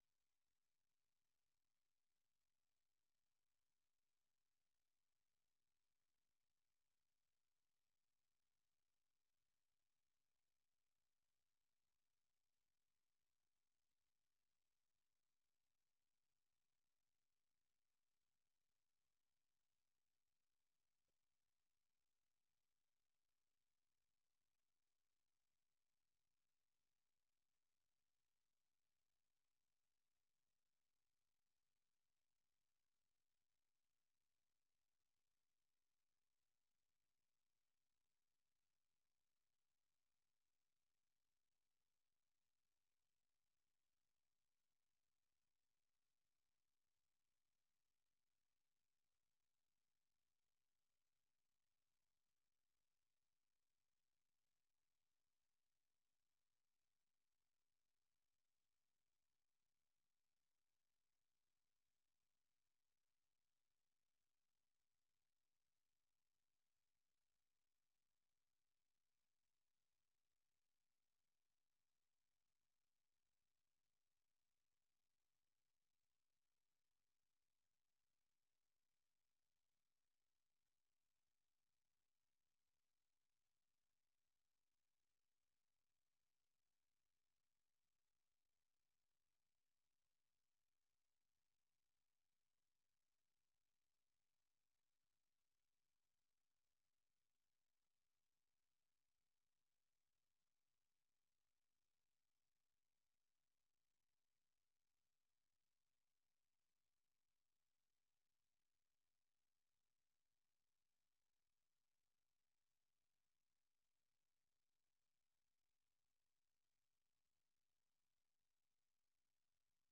Ejo ni ikiganiro cy'iminota 30 gitegurwa n'urubyiruko rwo mu Rwanda, kibanda ku bibazo binyuranye ruhura na byo. Ibyo birimo kwihangira imirimo, guteza imbere umuco wo kuganiro mu cyubahiro, no gushimangira ubumwe n'ubwiyunge mu karere k'ibiyaga bigari by'Afurika.